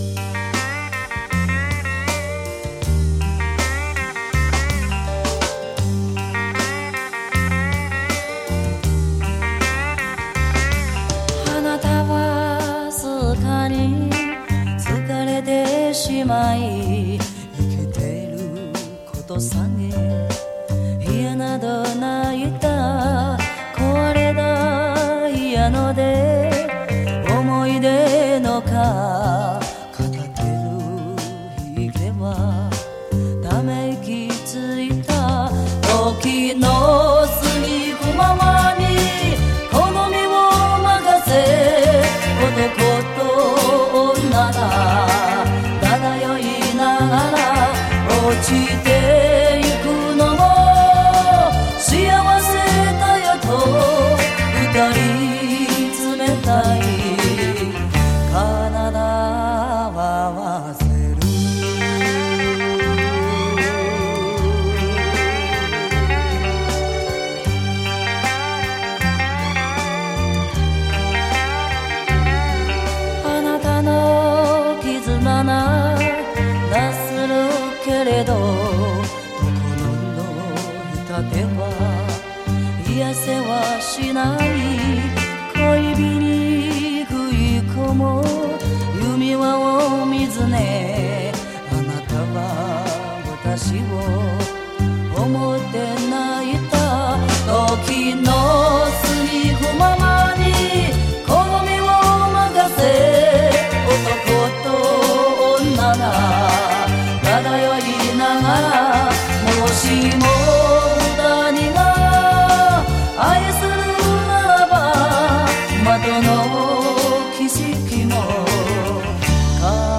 回味从小到大的磁性声音 惟有黑胶原版CD